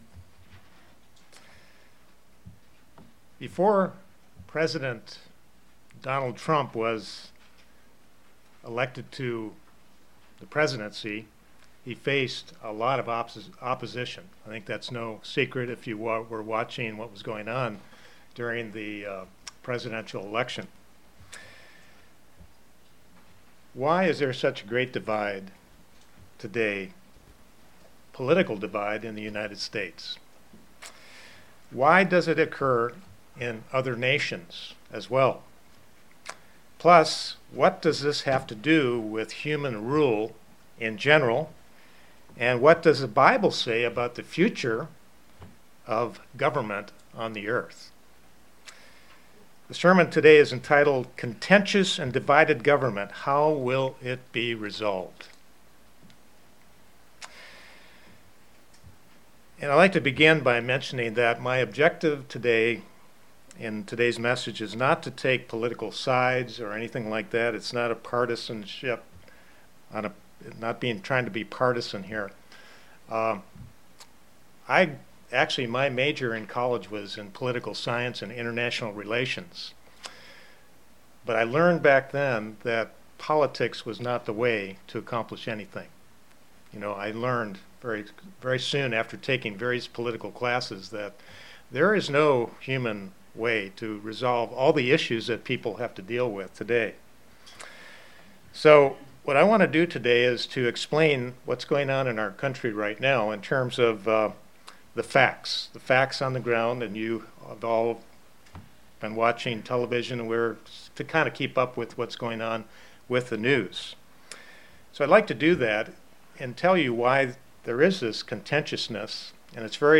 Sermons
Given in Kingsport, TN Knoxville, TN London, KY